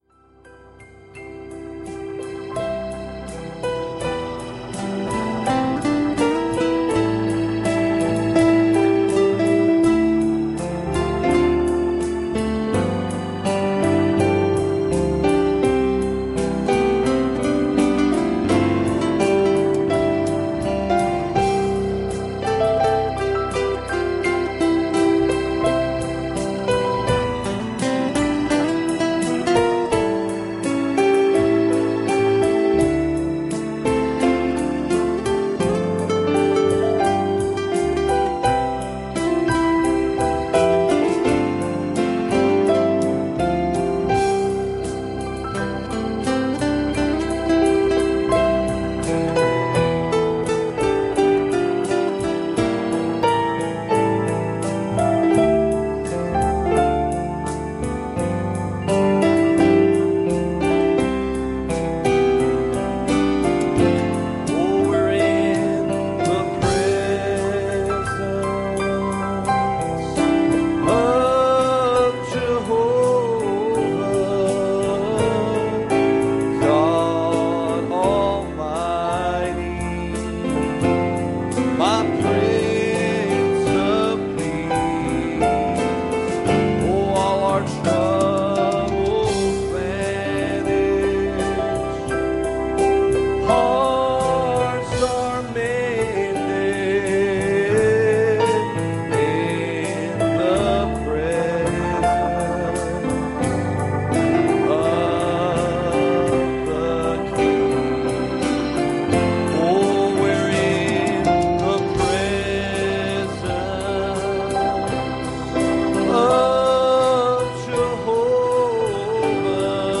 James 2:18 Service Type: Sunday Morning "Remember my father